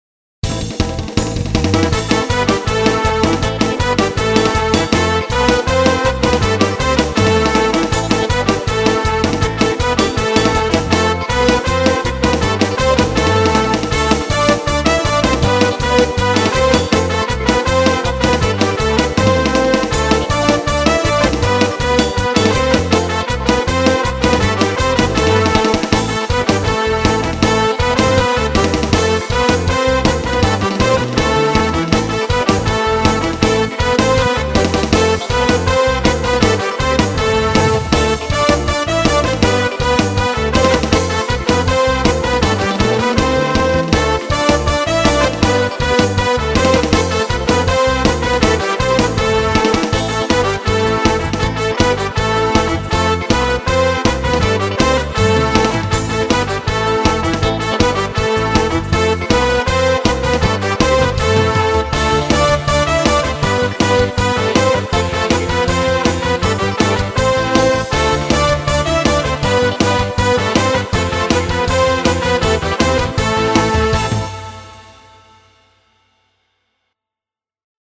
אל תתייחס לזיופים אני יתקן את זה מבחינת האקורדים והנגינה (אגב זה בסולם am תגיד לי אזה סולם וגם איזה מהירות כאן זה על 160)